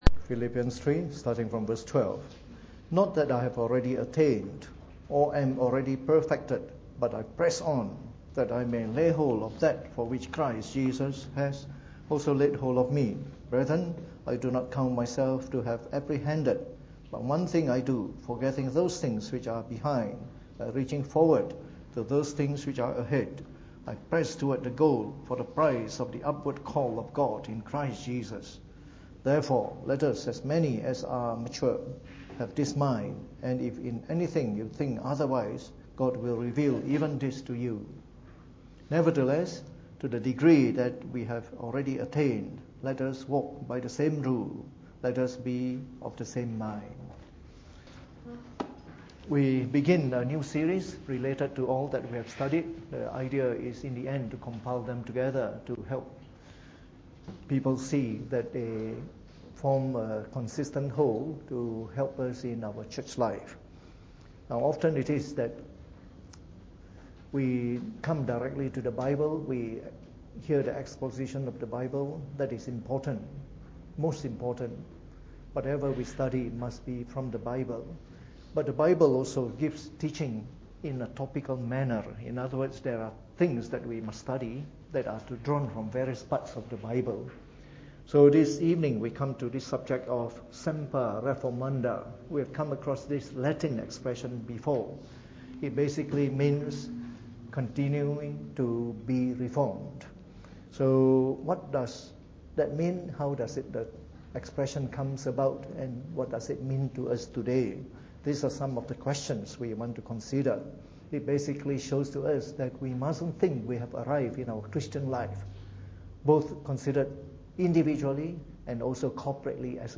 Preached on the 1st of March 2017 during the Bible Study, from our new series on Semper Reformanda.